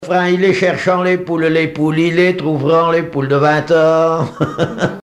Chants brefs - Conscription
Fonction d'après l'analyste gestuel : à marcher ;
Chansons traditionnelles et populaires
Pièce musicale inédite